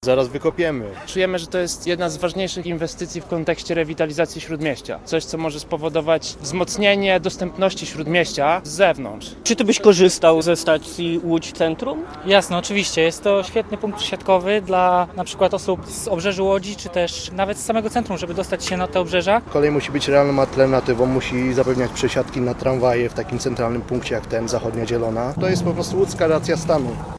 Nazwa Plik Autor Mówią uczestnicy happeningu audio (m4a) audio (oga) CZYTAJ WIĘCEJ: – Mieszkańcy Łodzi chcą podziemnej stacji.